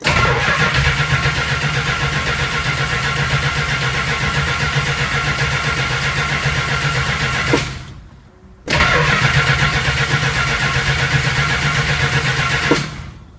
The resultant starter looks just like every other '74-'89 large-frame Chrysler OSGR starter, but doesn't sound like either the small-frame or the large-frame starter.
And you can listen to it cranking a 383 in a '66 Dodge,
3-Series_1-shunt_largeframe_Hot_Crank.mp3